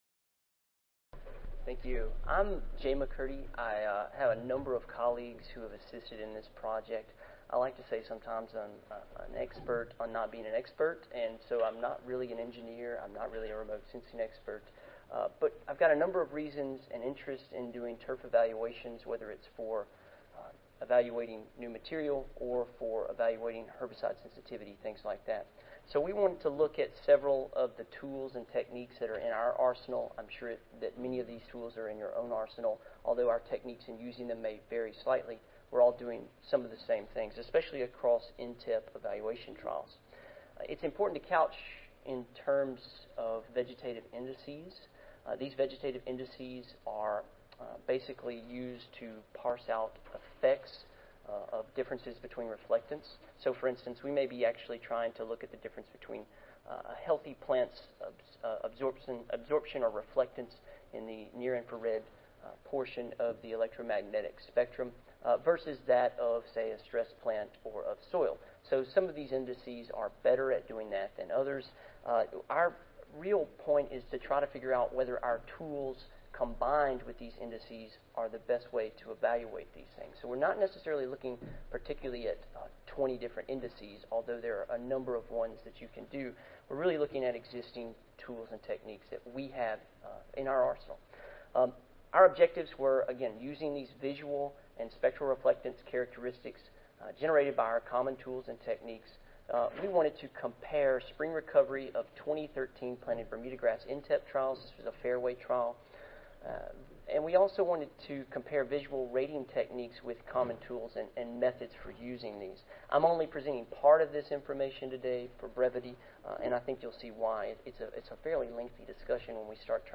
Session: Turfgrass Breeding and Genetics, Stress Tolerance (ASA, CSSA and SSSA International Annual Meetings (2015))
Recorded Presentation